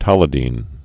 (tŏlĭ-dēn)